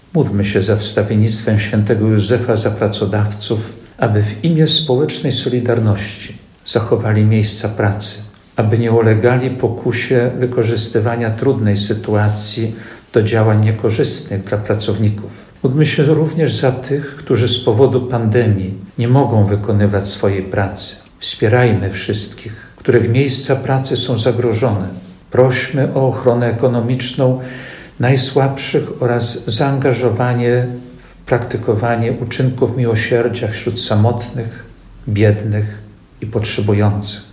– W obecnej chwili, święto pracodawców i ludzi pracy nabiera szczególnego znaczenia – mówi ks. Jerzy Mazur.